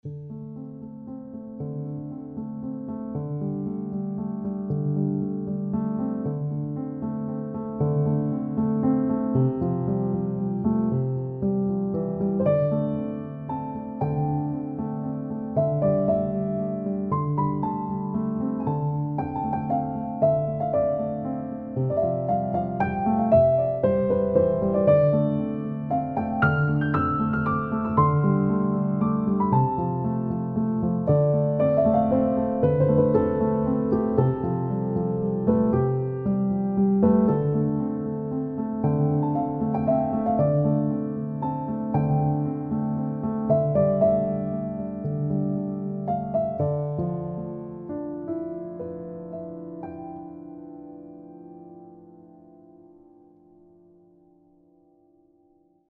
First Flight (Adventure/Fantasy)